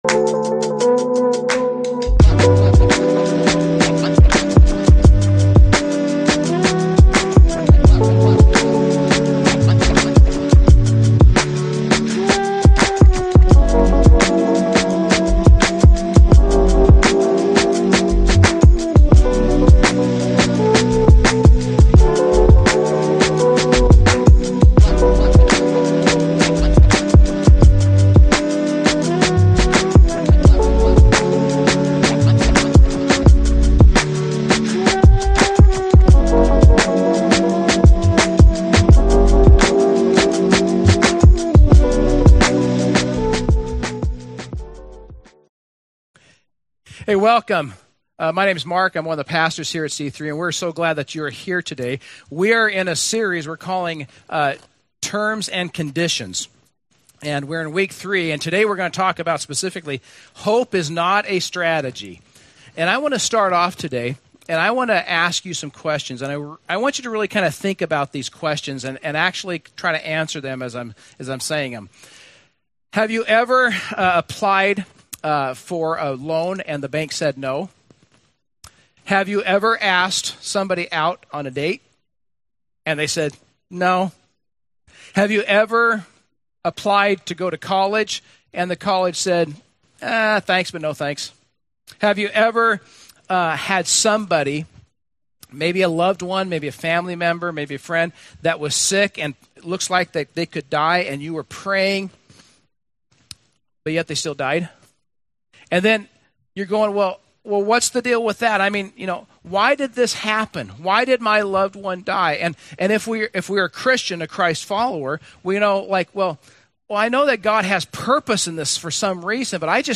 Hope-is-not-a-Strategy-message.mp3